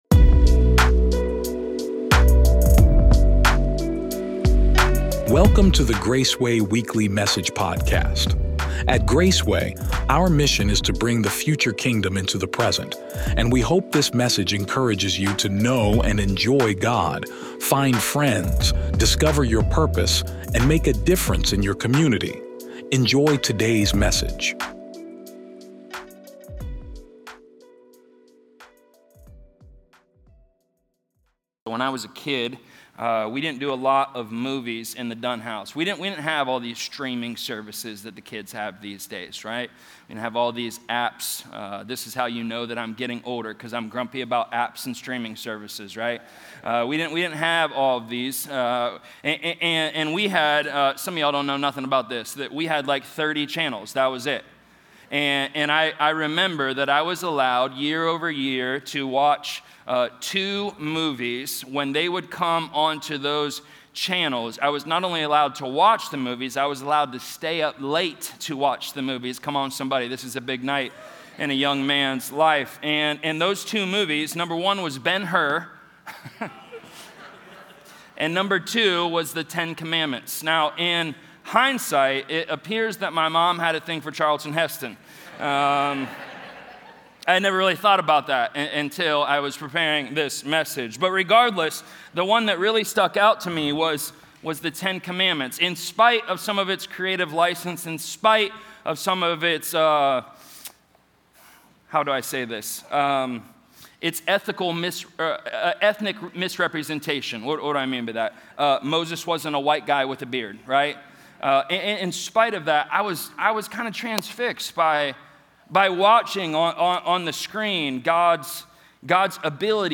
From the events of Pentecost, we see how God fulfilled His promise by empowering ordinary people to live out an extraordinary mission. This sermon explores the connection between Old Testament moments like Sinai and the New Testament outpouring of the Spirit, revealing God’s plan to form a people marked by grace, not law. Through Peter’s first sermon, we see repentance, salvation, and the launch of an unstoppable movement.